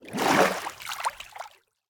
Minecraft Version Minecraft Version latest Latest Release | Latest Snapshot latest / assets / minecraft / sounds / ambient / underwater / exit1.ogg Compare With Compare With Latest Release | Latest Snapshot